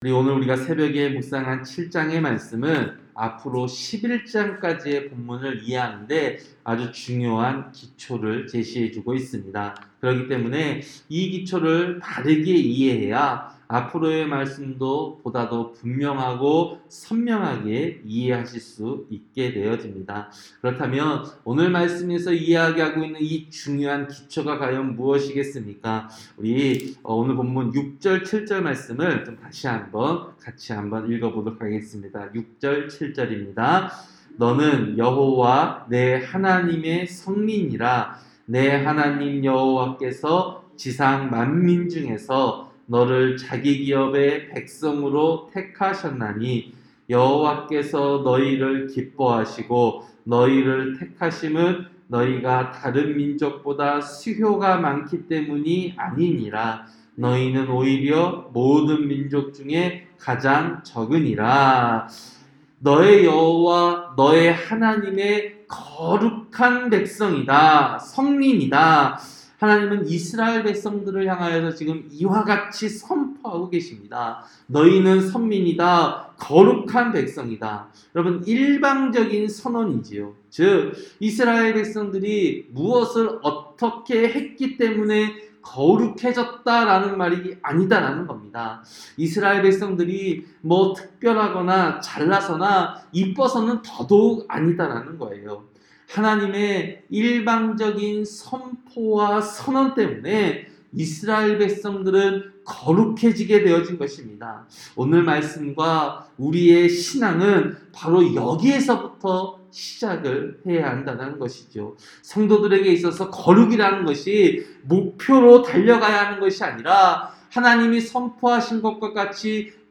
새벽설교-신명기 7장